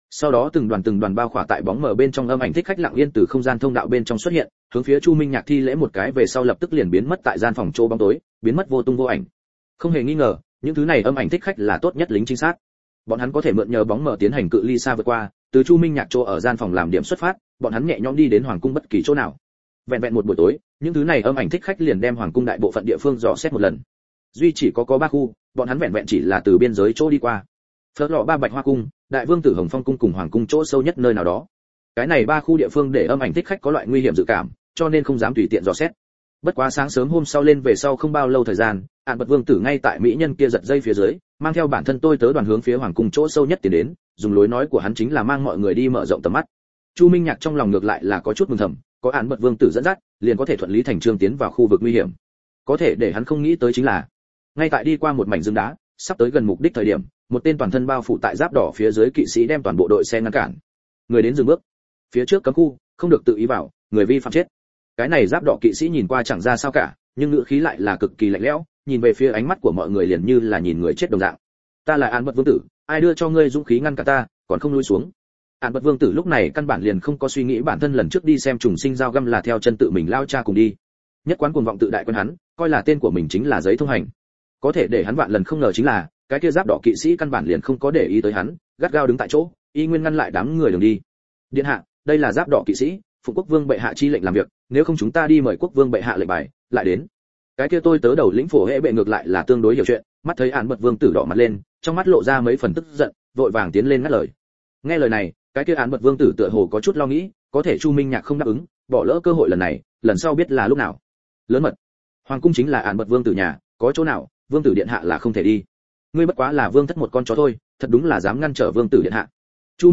Chiến Tranh Công Xưởng Audio - Nghe đọc Truyện Audio Online Hay Trên AUDIO TRUYỆN FULL